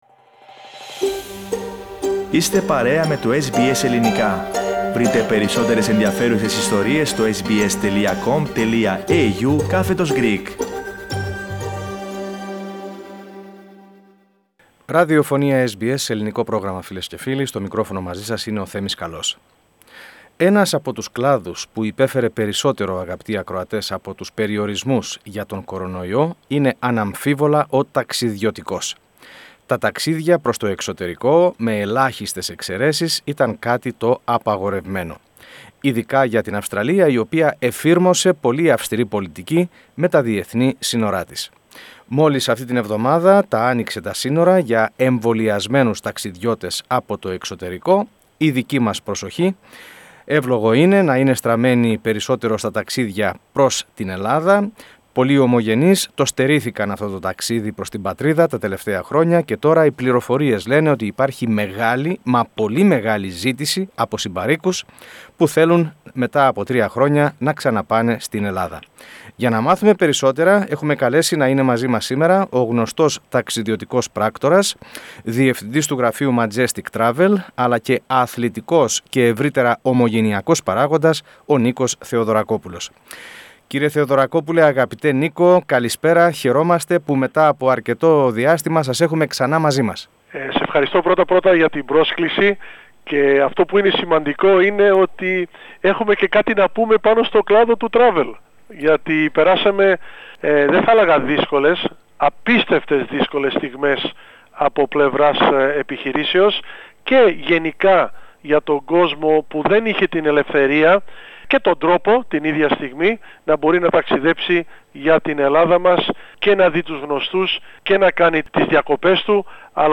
Με την Αυστραλία να έχει άρει πλέον τους ταξιδιωτικούς περιορισμούς, η προσοχή πολλών ομογενών είναι στραμμένη στο ταξίδι προς Ελλάδα. Έχει αυξηθεί όντως η ζήτηση; Πότε να κάνουμε κράτηση; Ταξιδιωτικός πράκτορας απαντά.